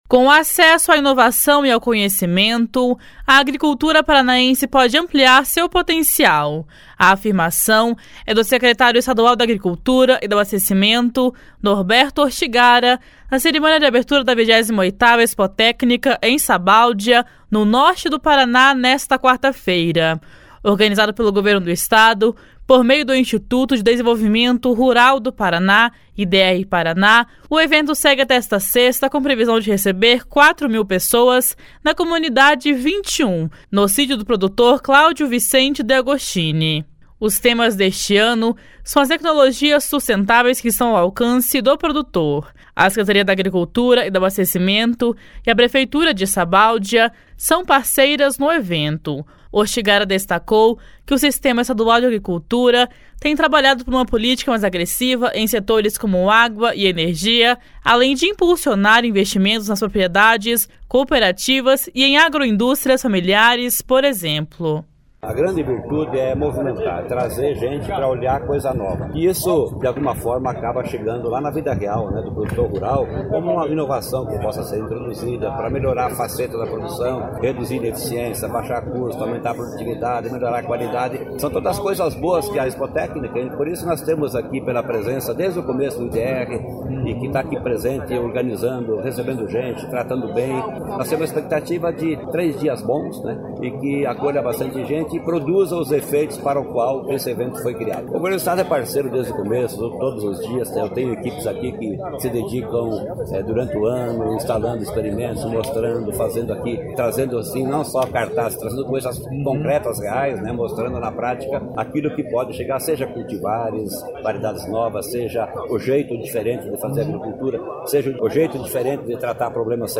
A afirmação é do secretário estadual da Agricultura e do Abastecimento, Norberto Ortigara, na cerimônia de abertura da 28ª Expotécnica, em Sabáudia, no Norte do Paraná, nesta quarta-feira.